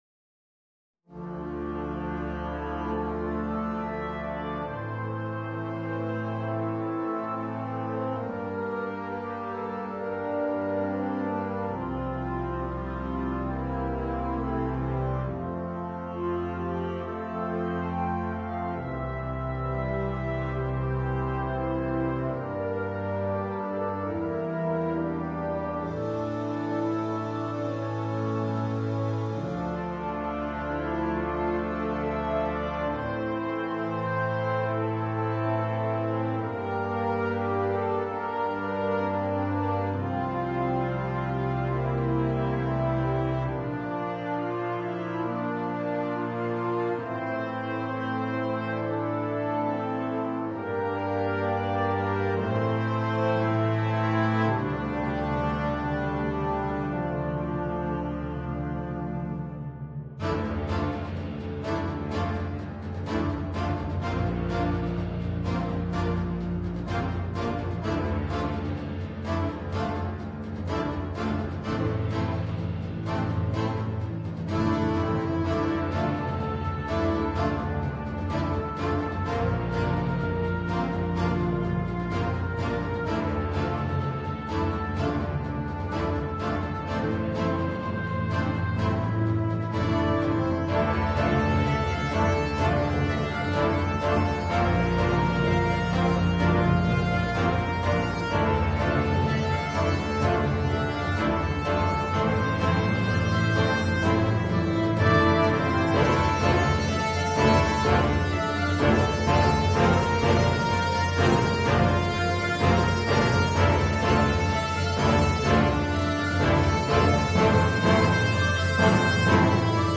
for concert band